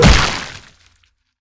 spidermonster_hit1.wav